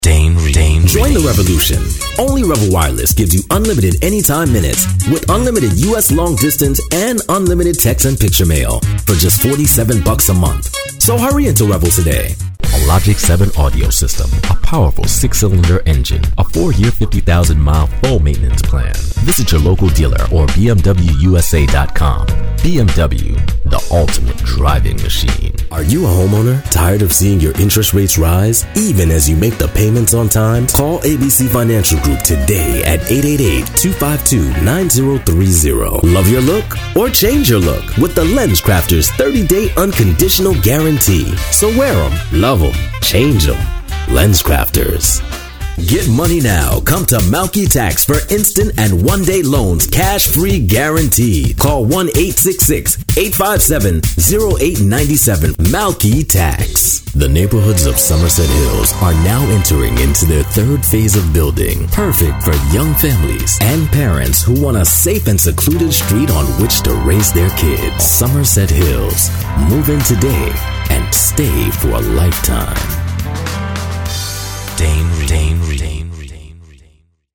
Strong, Versatile, African American, Urban, Caucasian, Caribbean, New York. Professional, Smooth.
mid-atlantic
Sprechprobe: Werbung (Muttersprache):